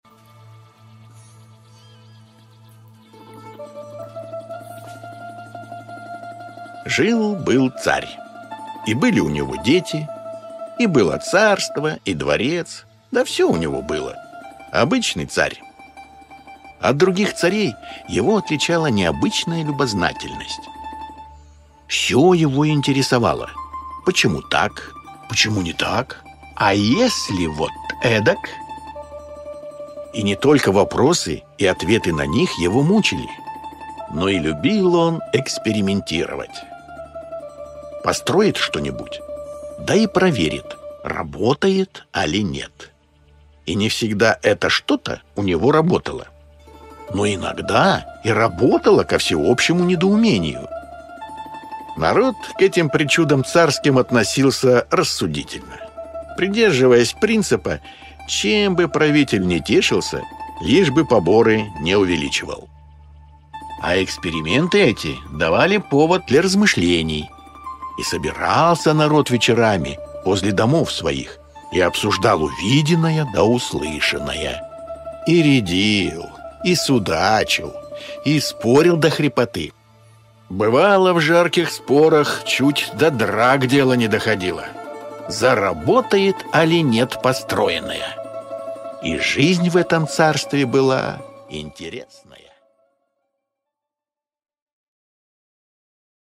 Аудиокнига Сказ про то, как Борис Александрович за знанием ходил | Библиотека аудиокниг